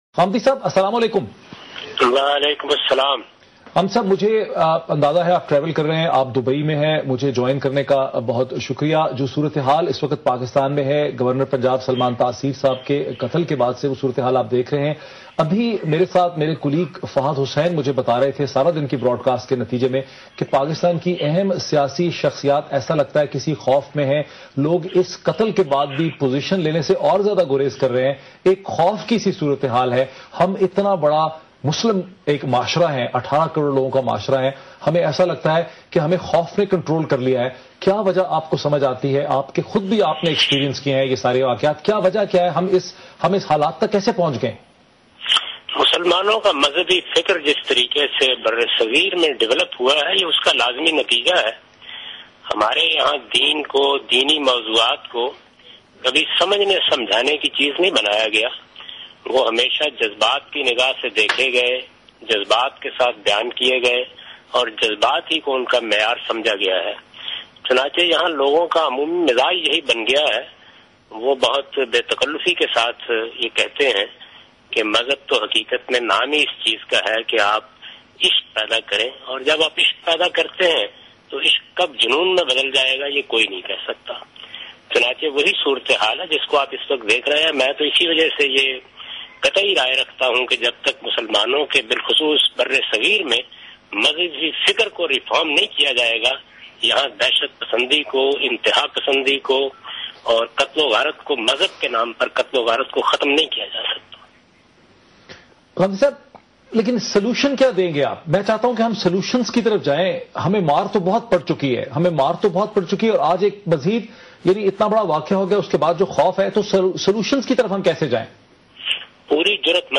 Category: TV Programs / Dunya News / Questions_Answers /
A discussion on "Religious Extremism and Murder of Salman Taseer" with Dr Moeed Pirzada on Dunya News.
مذہبی انتہا پسندی اور سلمان تاثیر کے قتل پر جاوید احمد غامدی اور ڈاکٹر معید پیرزادہ کے مابین گفتگو۔